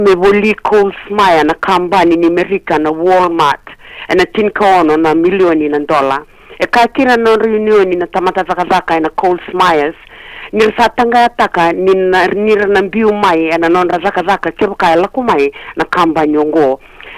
The recording is a news item from an online radio station.